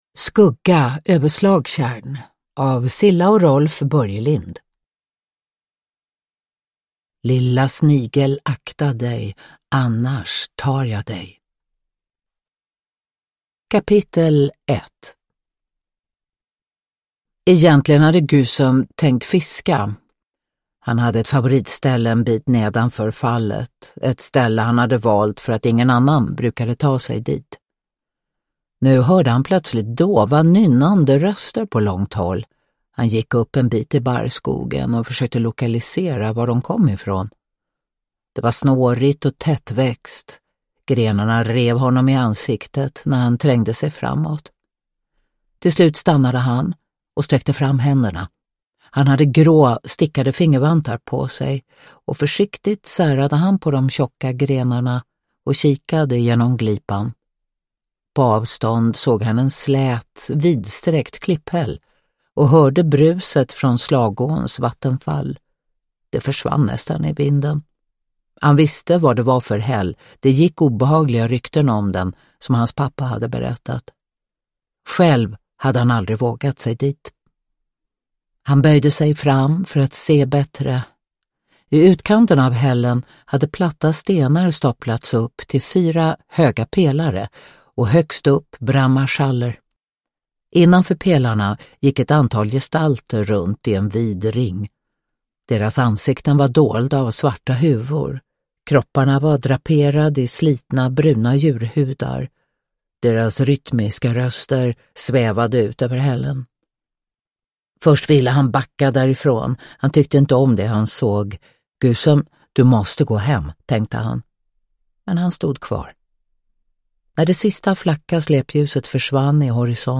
Skugga över Slagtjärn (ljudbok) av Rolf Börjlind